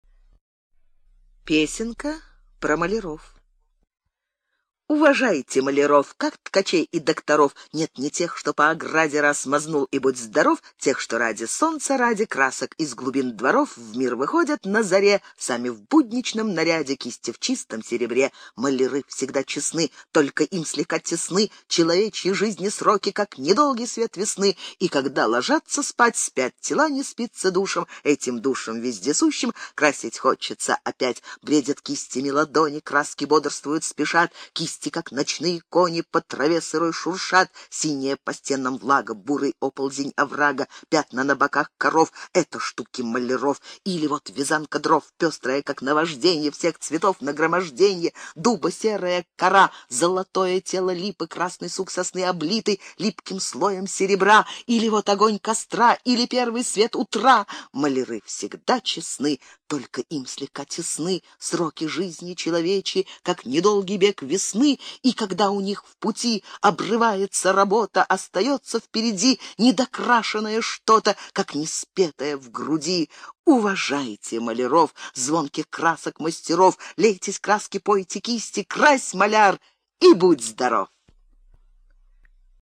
Жанр: Поэзия
Тип: Аудиокнига
Петь не умеет